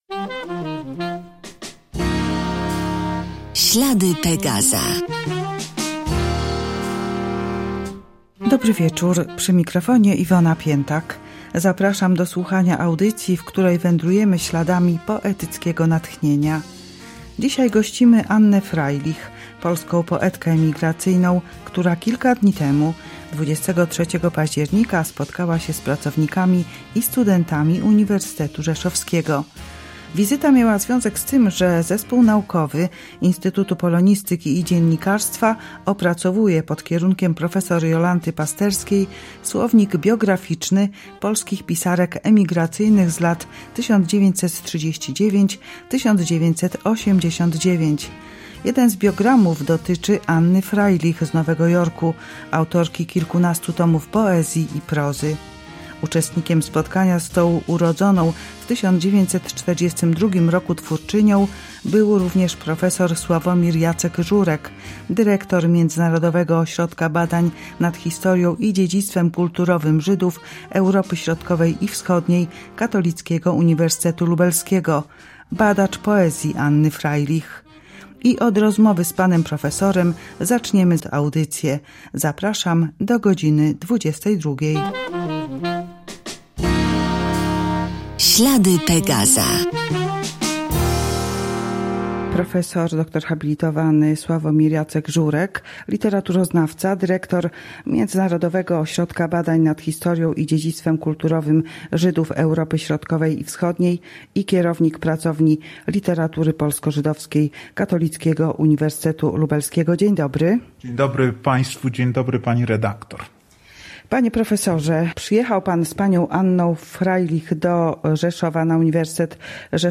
Urodzona w 1942 roku twórczyni, która opuściła Polskę w 1969 roku, po wydarzeniach Marca ’68 i fali nagonki antyżydowskiej, opowiedziała nam o swojej biografii i poezji oraz czytała swoje wiersze.